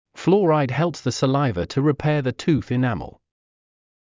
ﾌﾛｰﾗｲﾄﾞ ﾍﾙﾌﾟｽ ｻﾞ ｻﾗｲｳﾞｧ ﾄｩ ﾘﾍﾟｱ ｻﾞ ﾄｩｰｽ ｲﾅﾓｳ